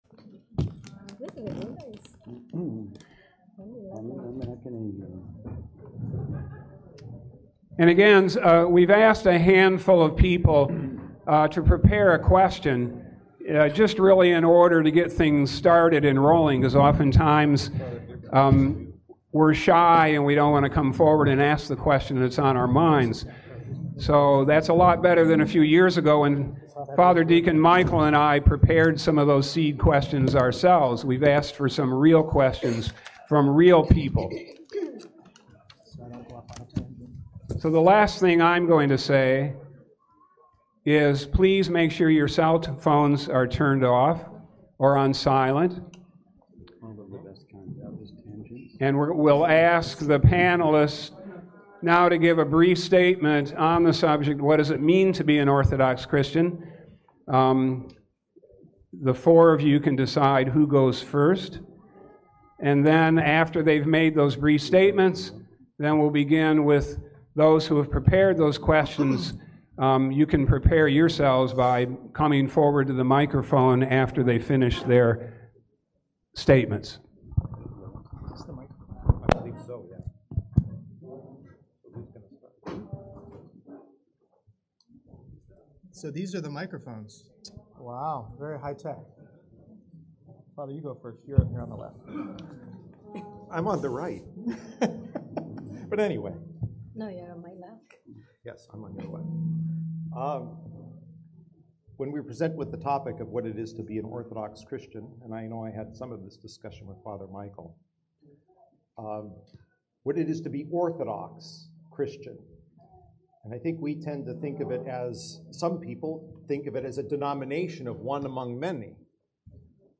We joined together for worship at Vespers, in sharing a meal and in in reciving spiritual nourishment from reflections on our theme: Ascending Mount Tabor.